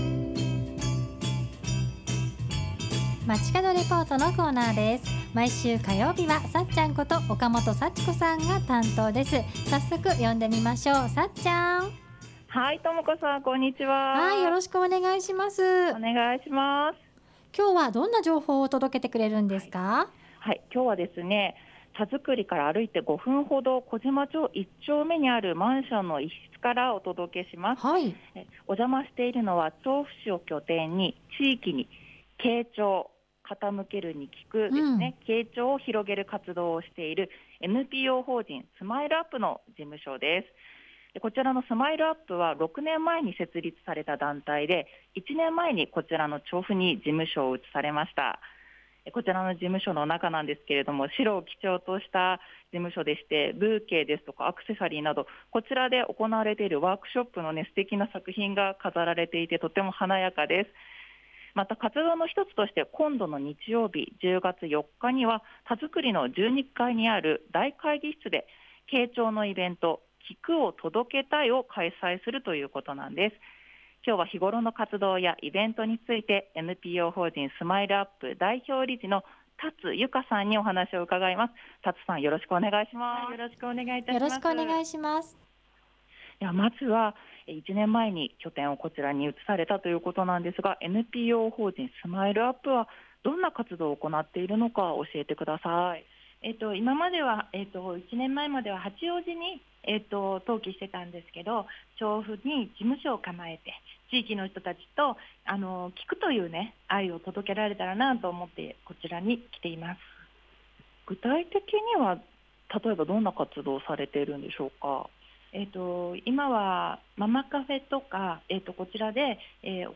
中継は、たづくりから歩いて5分ほど。 調布市を拠点に地域に「傾聴」を広げる活動をしているNPO法人Smile upの事務所にお邪魔しました。